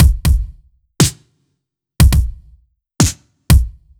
Index of /musicradar/french-house-chillout-samples/120bpm/Beats
FHC_BeatD_120-03_KickSnare.wav